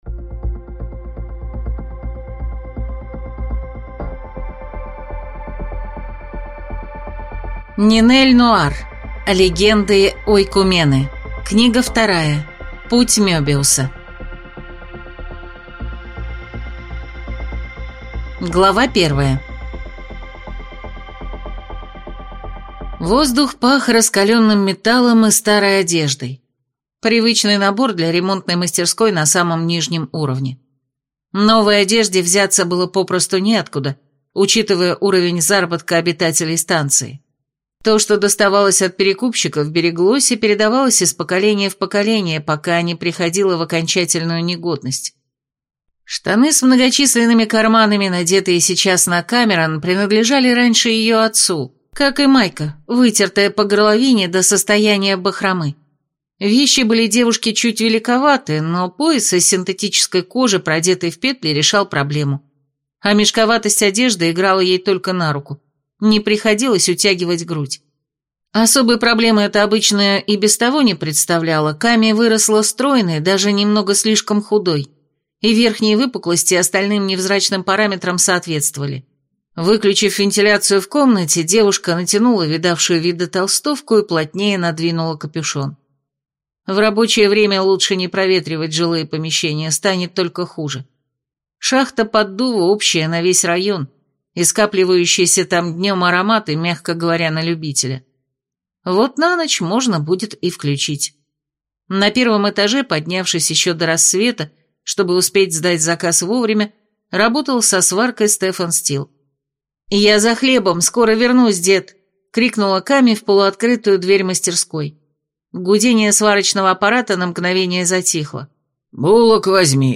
Аудиокнига Легенды Ойкумены. Книга 2. Путь Мебиуса | Библиотека аудиокниг